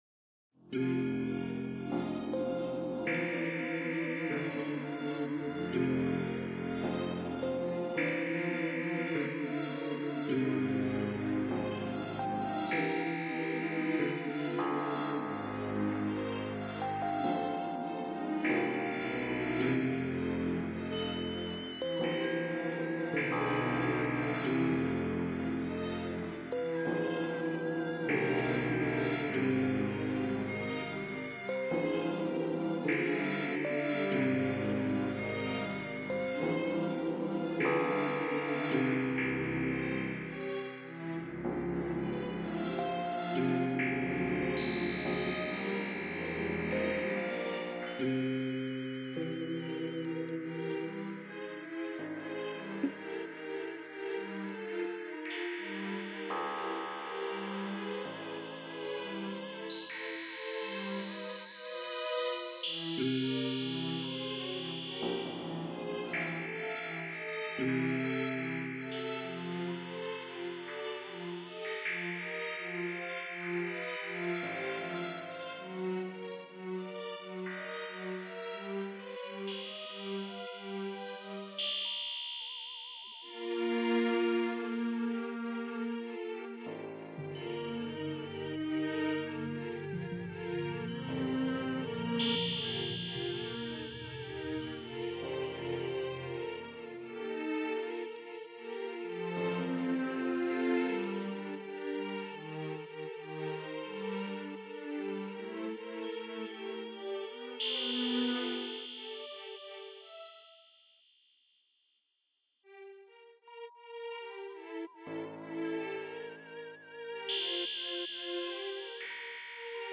All sorts of strange weird sounds.